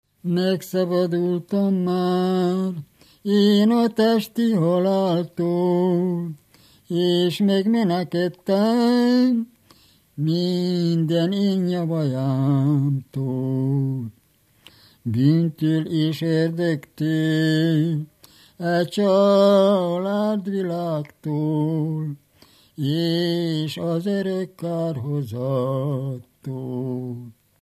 Moldva és Bukovina - Bukovina - Istensegíts
ének
Stílus: 4. Sirató stílusú dallamok
Kadencia: 4 (1) b3 1